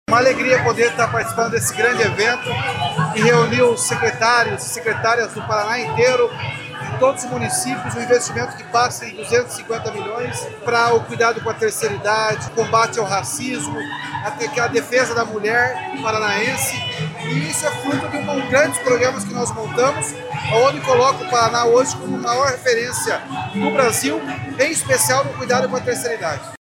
Sonora do governador Ratinho Junior sobre as ações para fortalecer a igualdade racial e a política da pessoa idosa no Estado